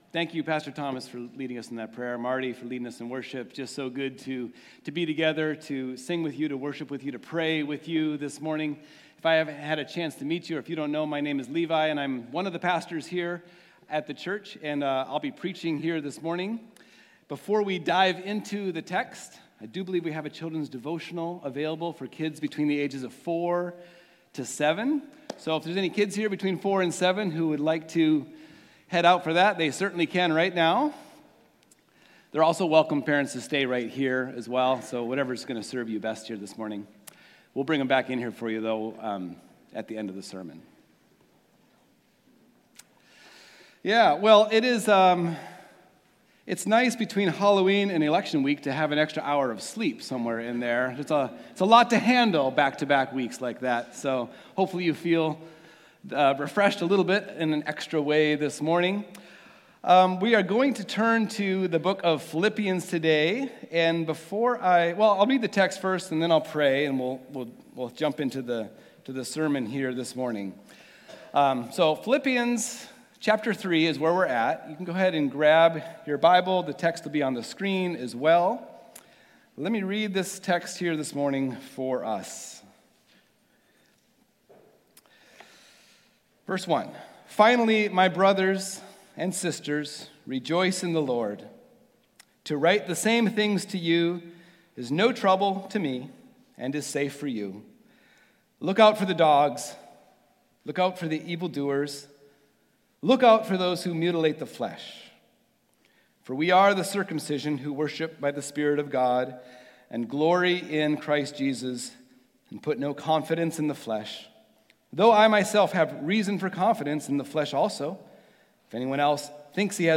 Christ Redeemer Church - Sunday Sermons